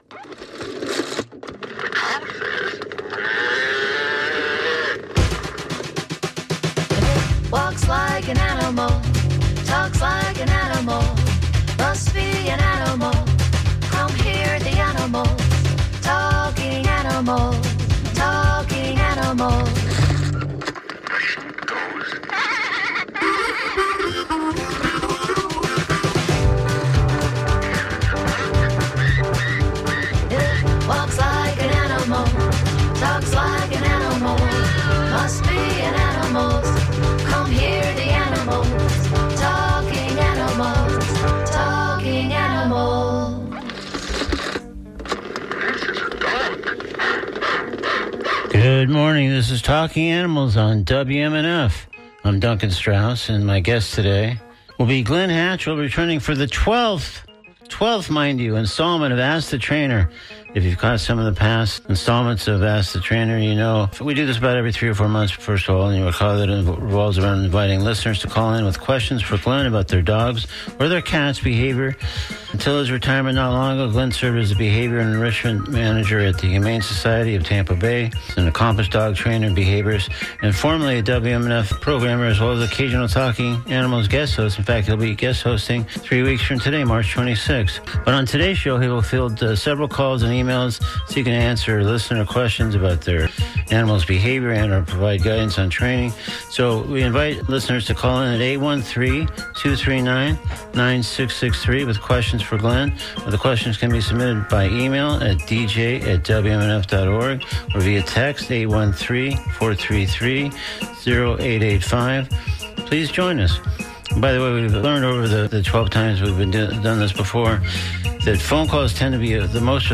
In each instance, listeners are invited to call or email questions about their dogs or cats, particularly involving behavioral issues or training concerns.